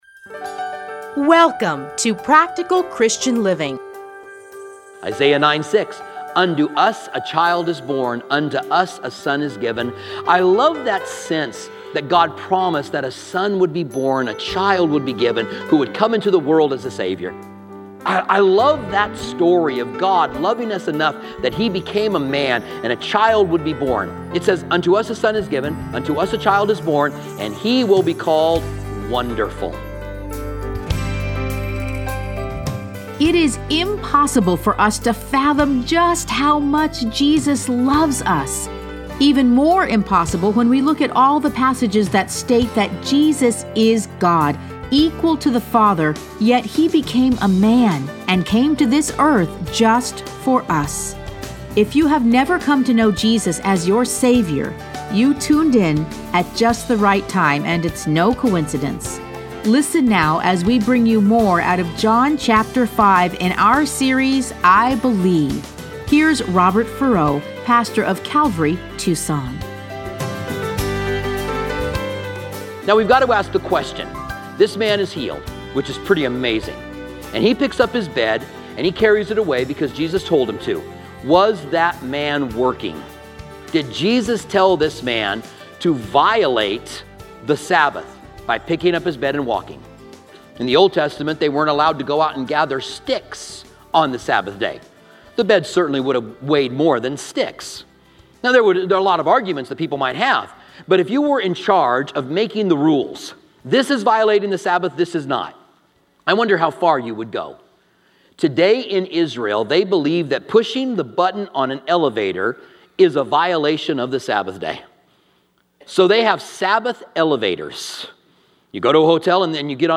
Listen to a teaching from John 5:1-30.